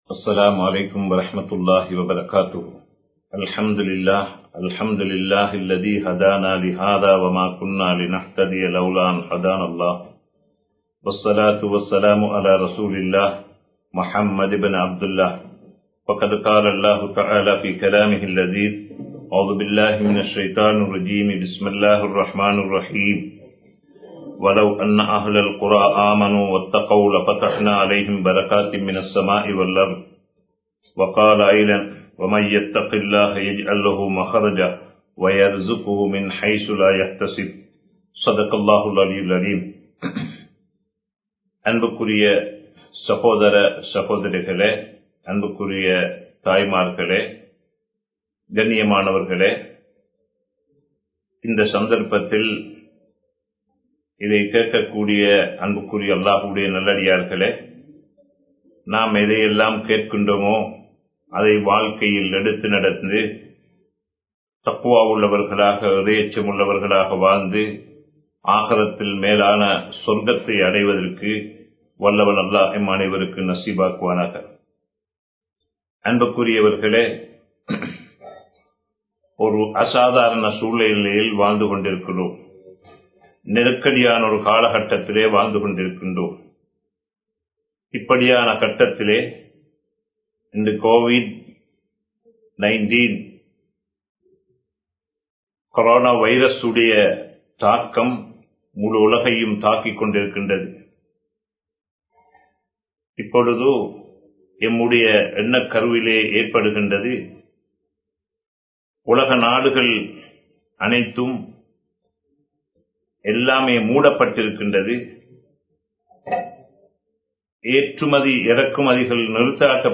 06 Vidayangalil Emathu Unavu Thangi Ullathu (06 விடயங்களில் எமது உணவு தங்கியுள்ளது) | Audio Bayans | All Ceylon Muslim Youth Community | Addalaichenai
Live Stream